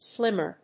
音節slím・mer 発音記号・読み方
/ˈslɪmɝ(米国英語), ˈslɪmɜ:(英国英語)/